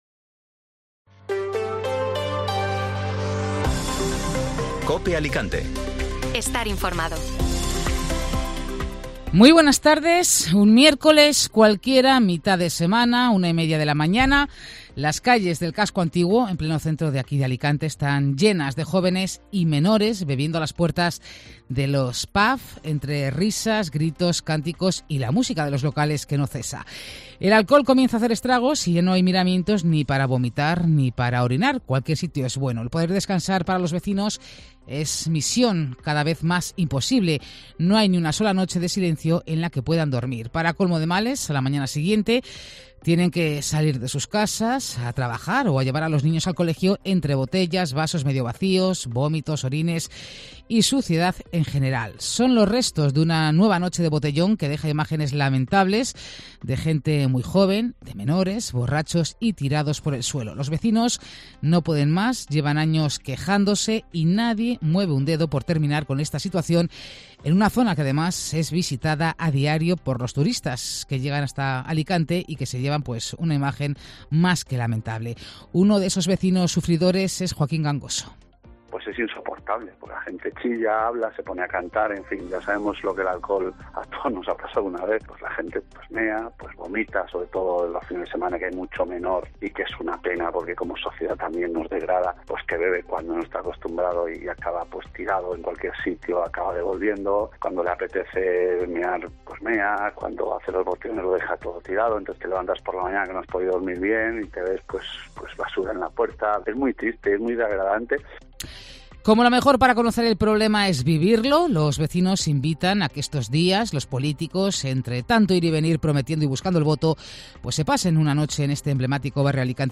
Informativo Mediodía Cope Alicante (Viernes 19 de mayo)